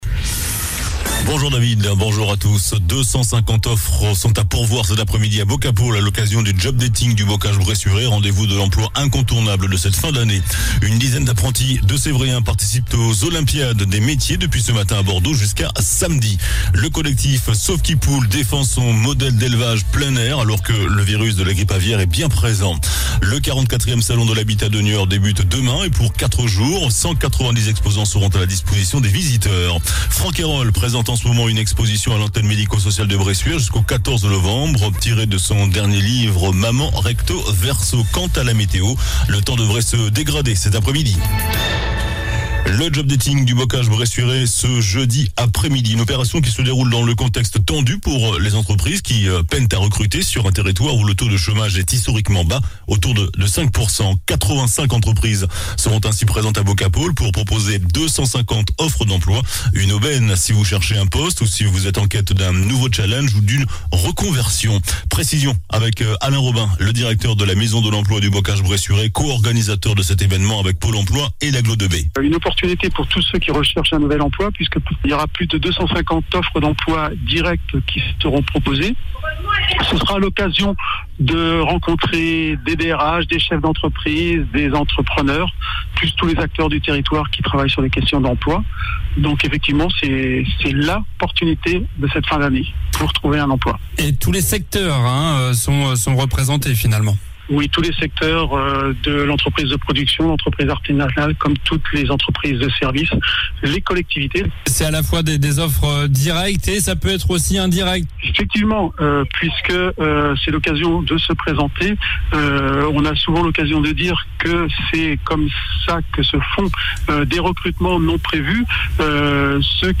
JOURNAL DU JEUDI 20 OCTOBRE ( MIDI )